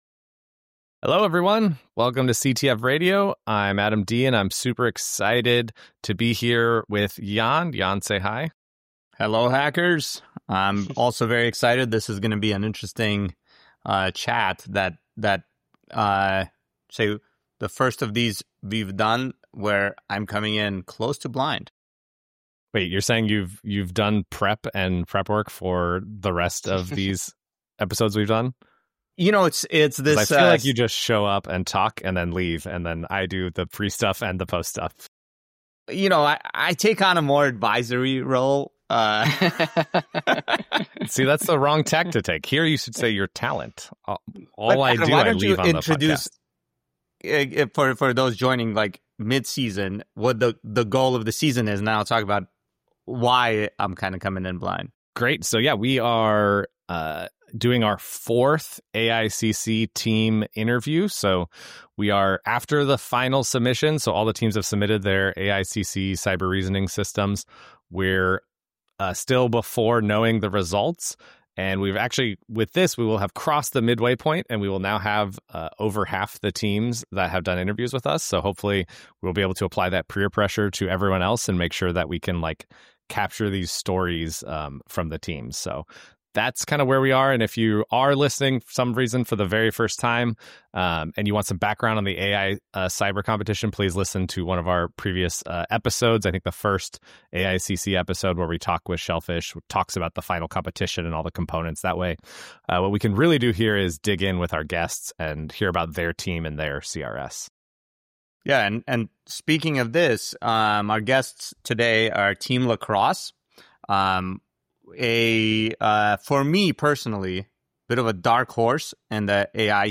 Capture The Flag Radiooo is a cybersecurity podcast focused on CTF competitions.